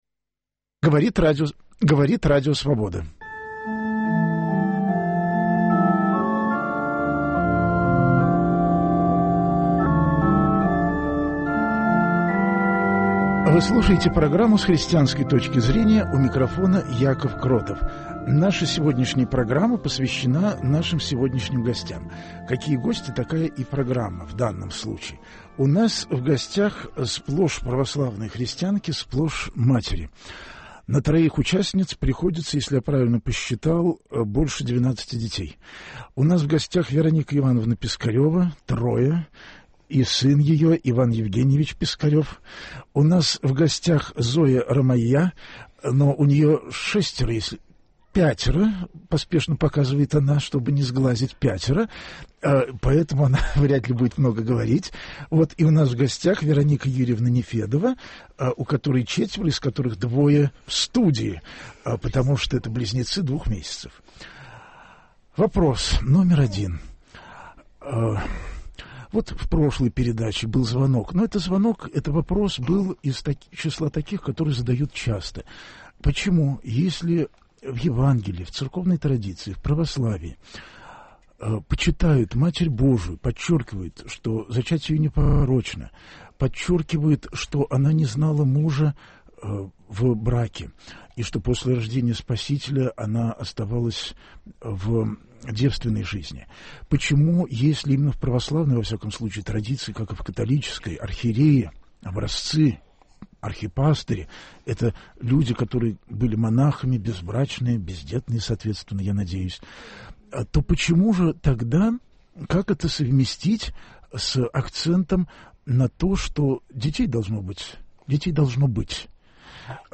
Гостями студии будет православная молодёжь, а разговор пойдёт о том, как сочетается почитание Богородицы, у которой был всего один ребёнок, обет безбрачия у церковной иерархии и монашества - и обилие детей, которым так часто грешат православные.